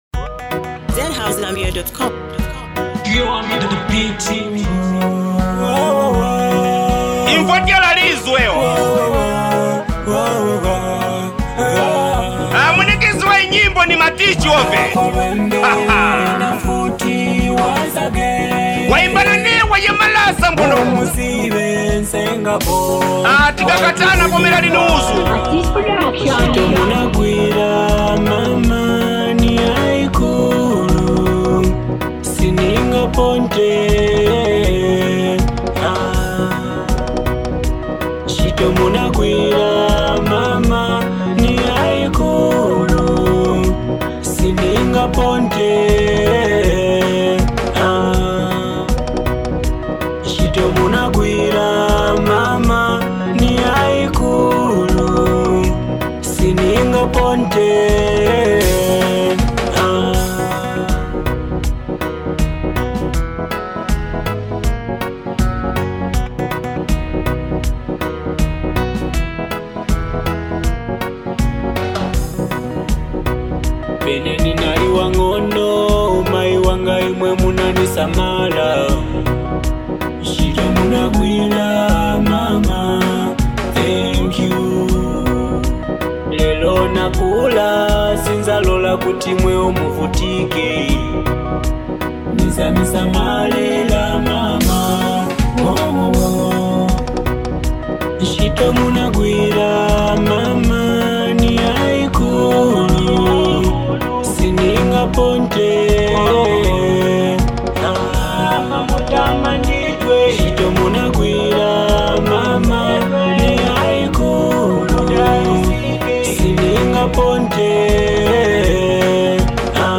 heartfelt vibe